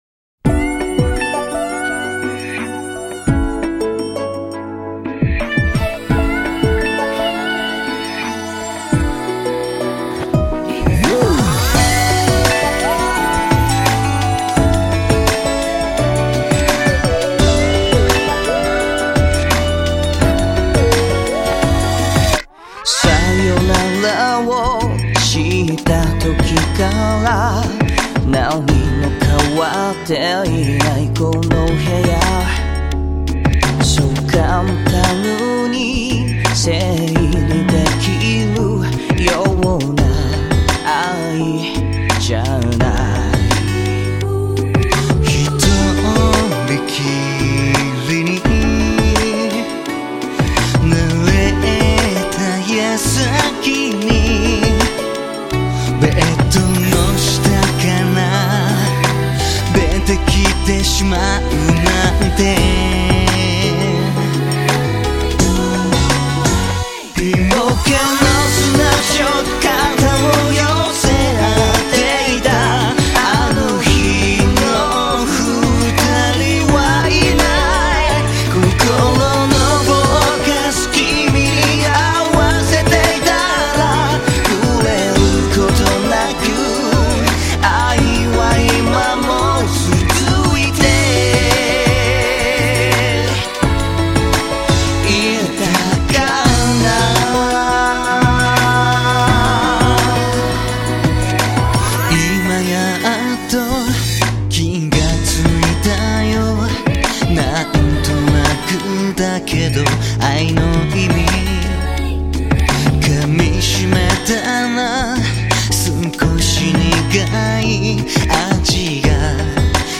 이 노래도 정말 코러스가 너무 좋다.